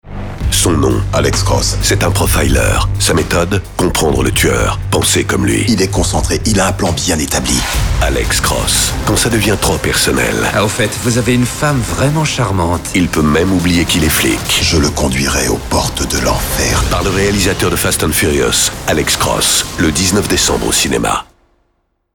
Spots radio 4